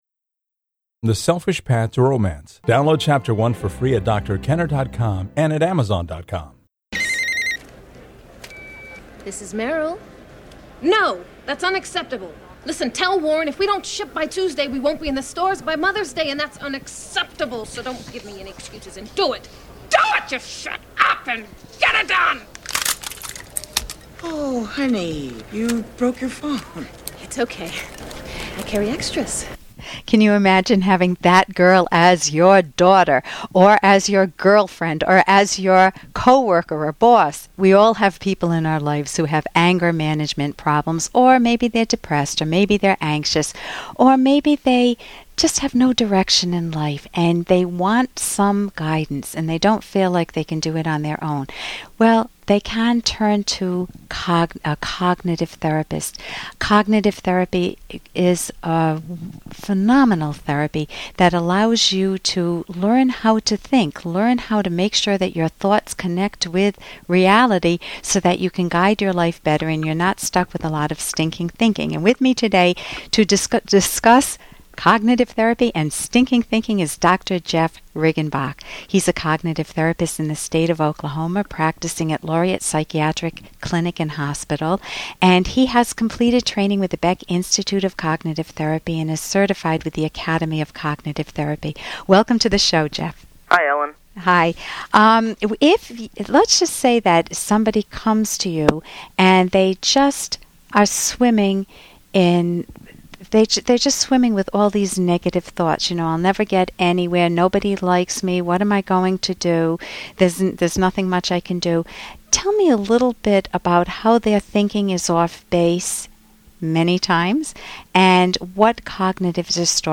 Bad Thoughts ~ Dealing with overpowering lousy thoughts - A short interview